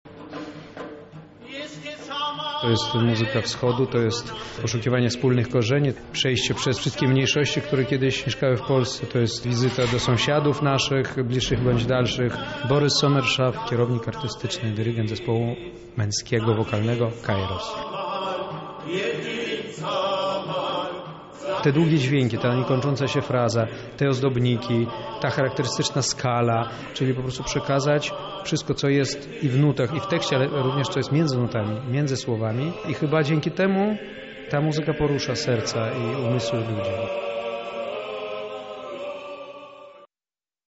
We wtorek wieczorem wirydarz lubelskiego Centrum Kultury gościł niezwykłych artystów.
Kairos to męska grupa wokalna, która swój repertuar zbudowała na dawnych pieśniach cerkiewnych, liturgiach gruzińskich, czy ormiańskich.
Koncert odbył się w ramach Festiwalu Integracje – Mediacje mającego na celu ukazanie tradycji i kultur krajów Partnerstwa Wschodniego.